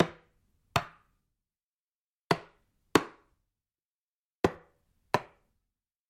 Звуки хода шахматного коня